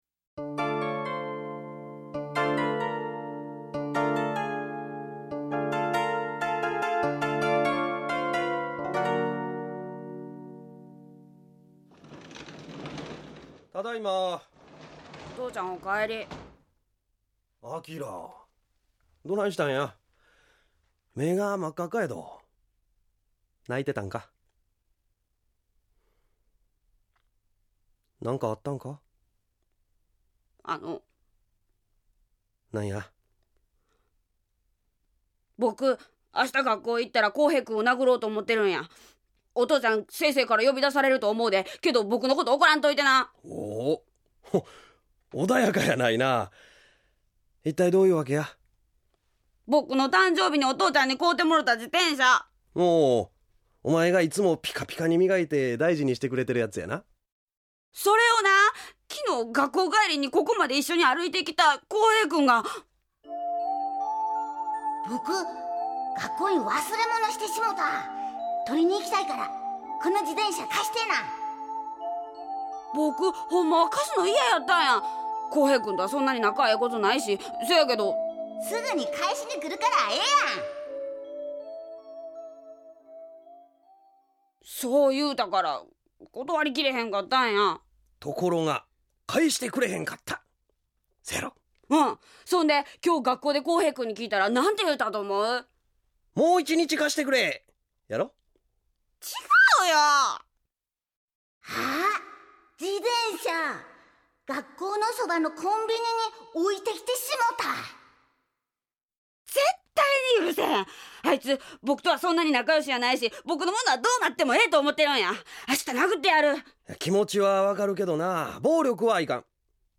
●ラジオドラマ「LIFE」